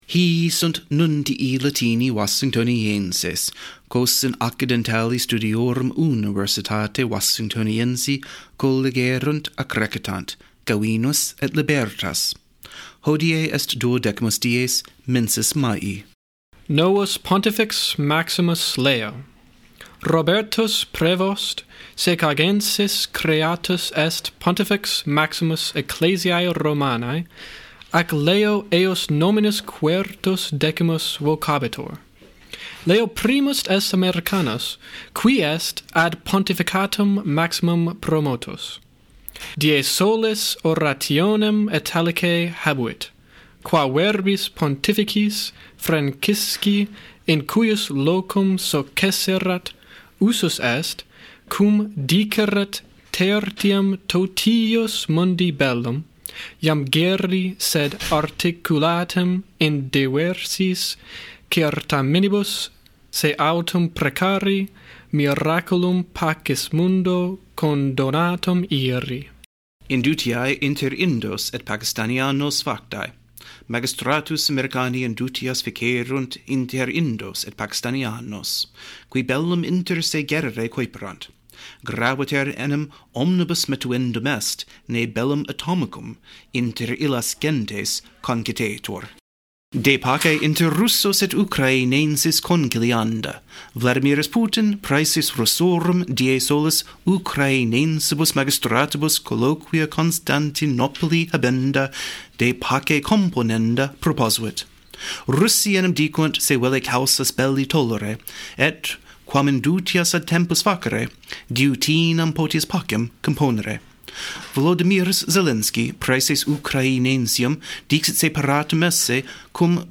Genres: Daily News, Education, Language Learning, News